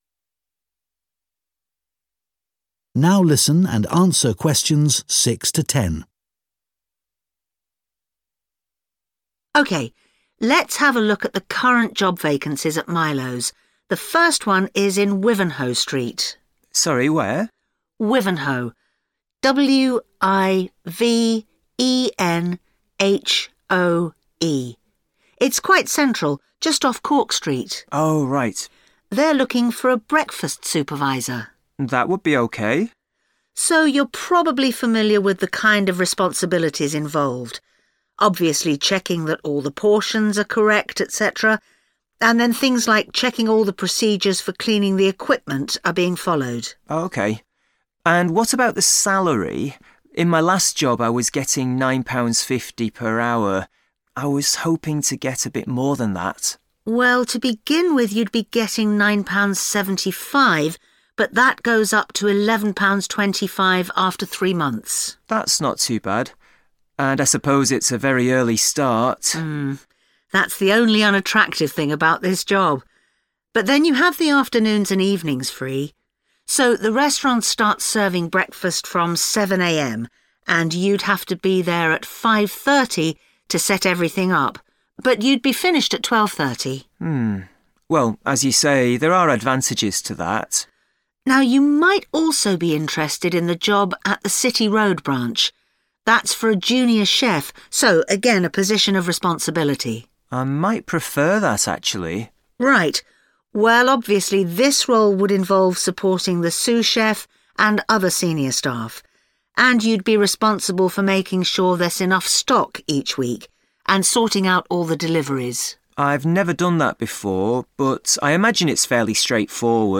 Example: IELTS Listening Table Completion Questions
In the audio, the speaker spells out Wivenhoe, making it clear this is a precise detail for the blank.